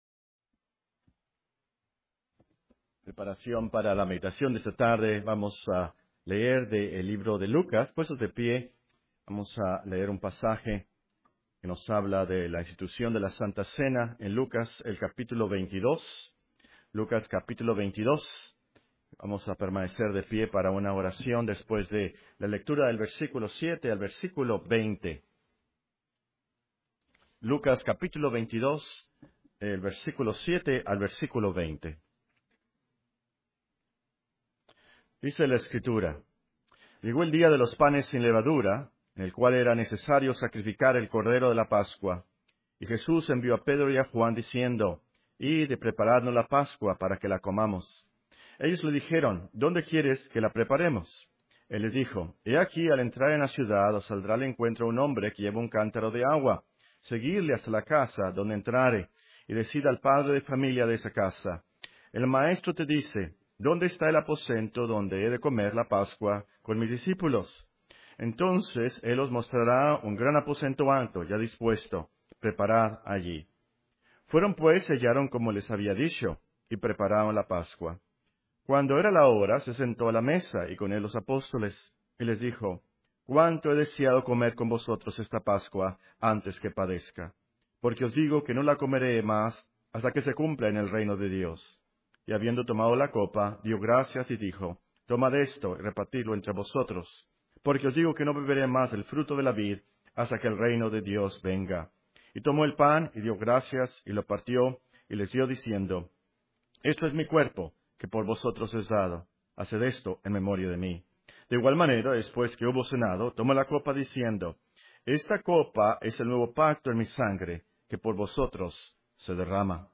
Serie de sermones Santa Cena